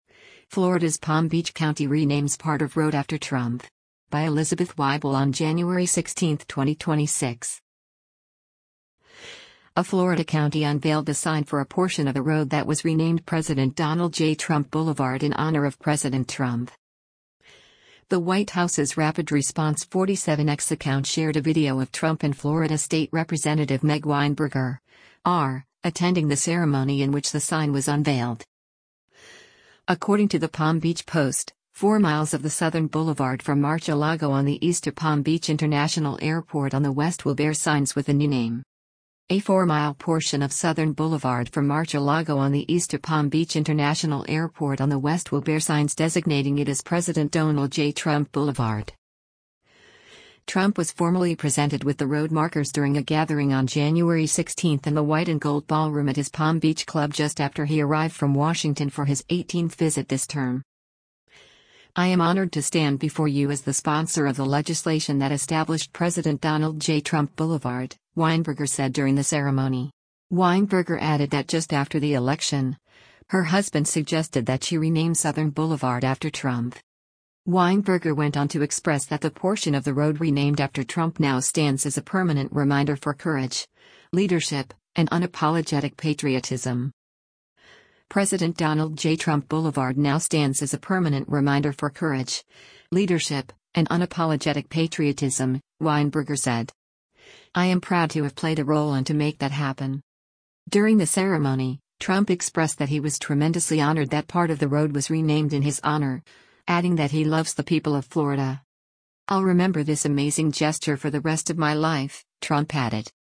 The White House’s Rapid Response 47 X account shared a video of Trump and Florida state Rep. Meg Weinberger (R) attending the ceremony in which the sign was unveiled.
Trump was formally presented with the road markers during a gathering on Jan. 16 in the White & Gold Ballroom at his Palm Beach club just after he arrived from Washington for his 18th visit this term.